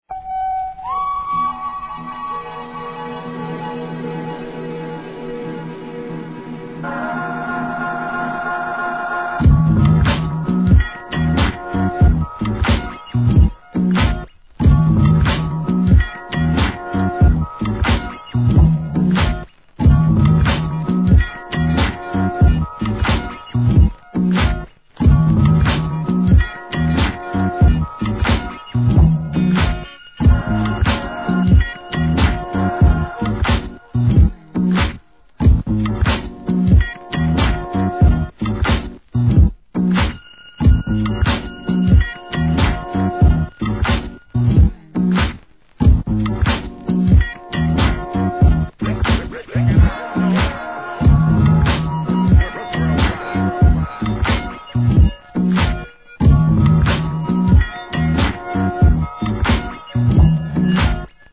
HIP HOP/R&B
一切ヴォーカルを入れず、完全にインストのみで自身の世界を創り上げた逸品!!